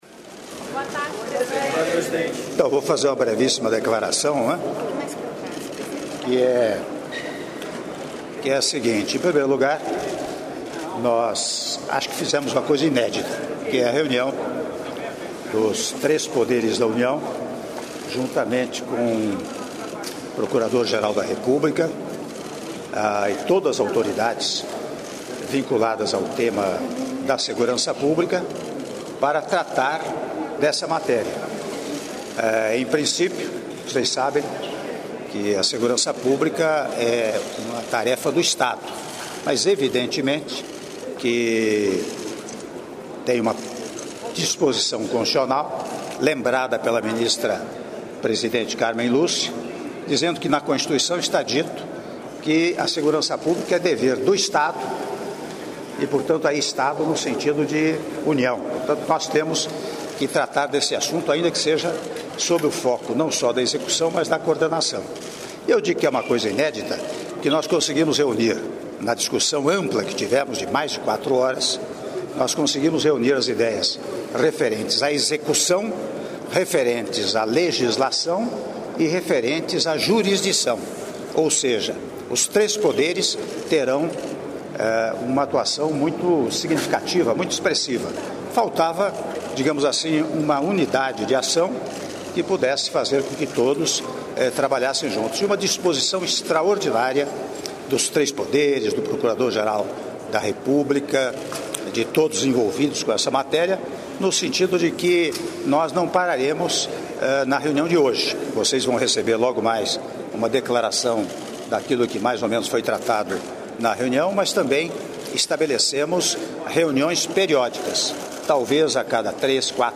Áudio da entrevista coletiva concedida pelo presidente da República, Michel Temer, após Reunião sobre Segurança Pública - (03min22s) - Brasília/DF